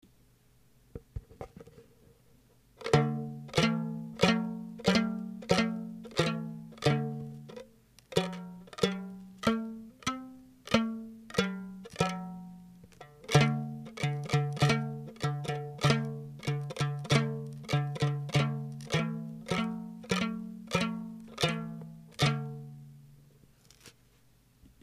このさおの両端にそれぞれ2箇所の切り込みを入れて輪ゴムをひっかけるだけなのですが、さおの上部の切り込みのひとつを　さおの途中に入れると　2本の弦の長さが変えられます。
2本の弦を同時に弾くと　より三味線っぽい音がし、発泡スチロールのトレイを三味線のばち型に切り抜いたばちで弾くと　さらにそれっぽくなって　ステキ！です。